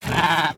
Minecraft Version Minecraft Version snapshot Latest Release | Latest Snapshot snapshot / assets / minecraft / sounds / mob / llama / angry1.ogg Compare With Compare With Latest Release | Latest Snapshot
angry1.ogg